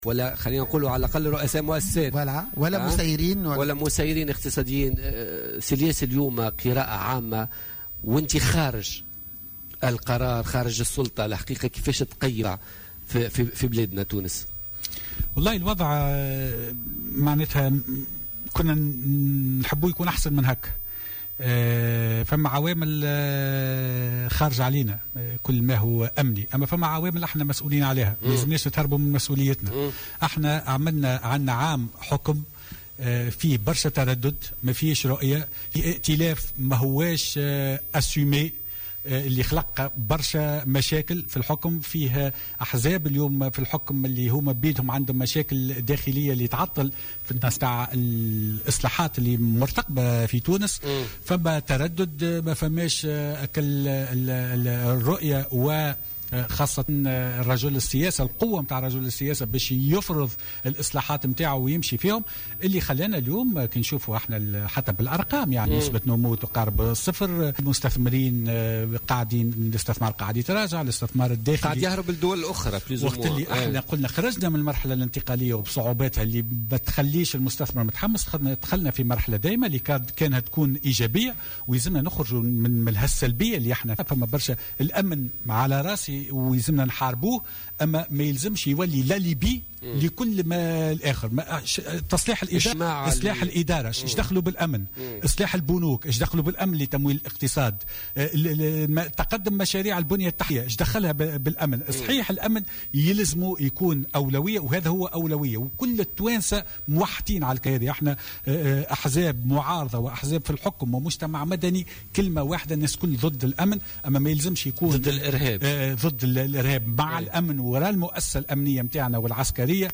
شدد رجل الأعمال والوزير الأسبق الياس الفخفاخ في مداخلة له في بوليتيكا اليوم الجمعة 4 نوفمبر 2015 أن تونس تعاني من غياب قوة الرجل السياسي القادر على فرض اصلاحات جذرية على اخراج البلاد من أزمتها الإقتصادية وإحداث الإصلاحات المرتقبة والمرجوة مشيرا إلى وجود تردد كبير وغياب للإرادة السياسية.